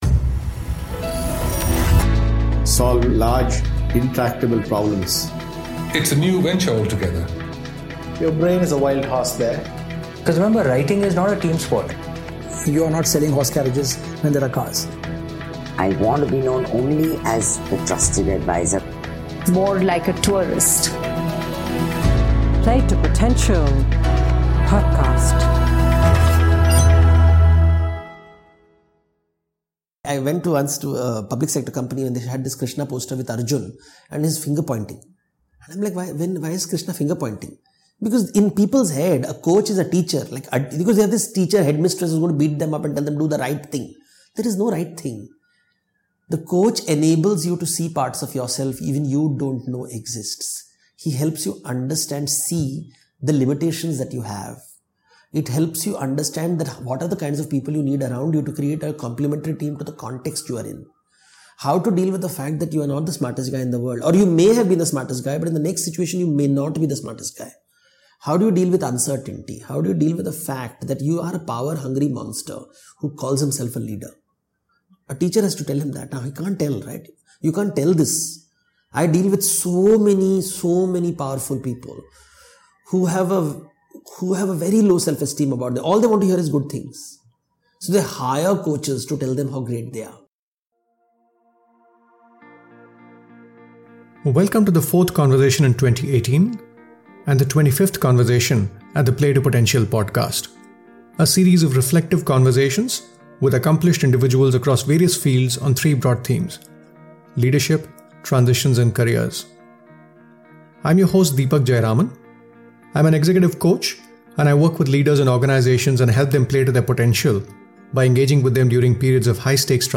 In our conversation, Devdutt spoke about how he transitioned from the world of Medicine and Healthcare to the world of mythology.